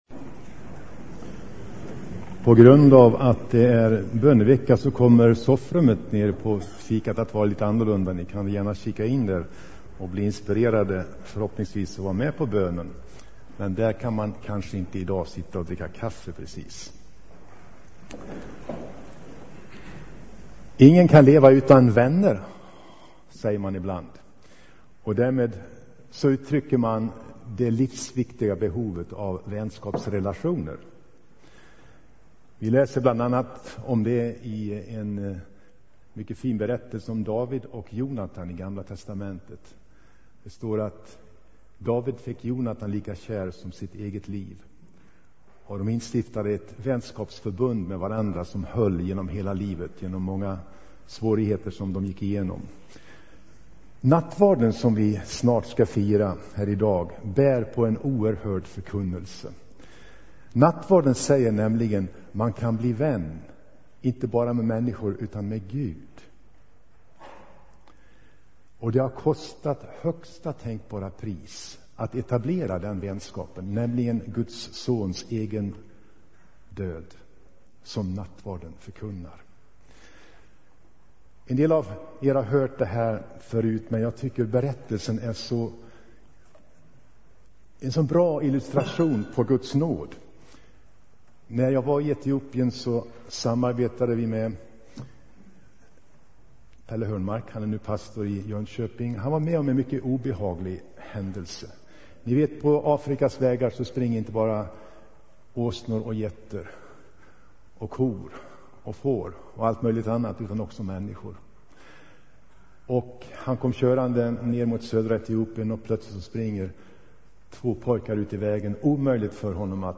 Inspelad i Tabernaklet i Göteborg 2007-05-06.